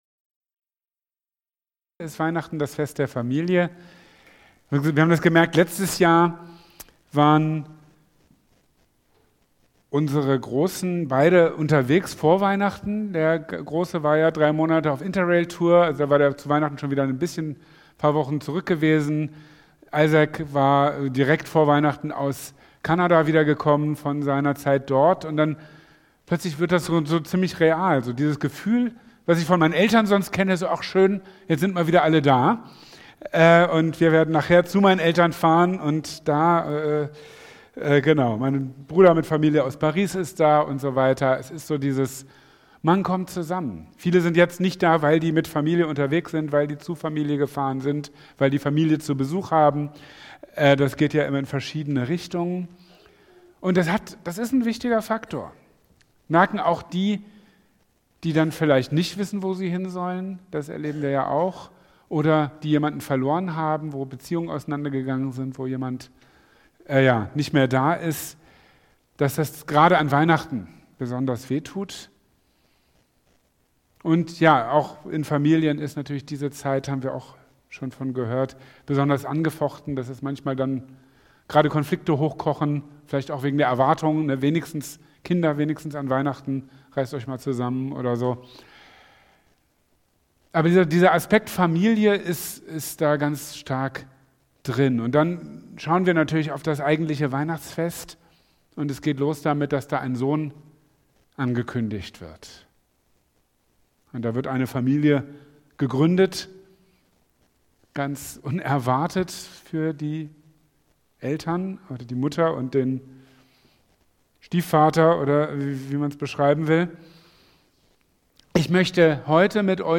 Drei Söhne | Marburger Predigten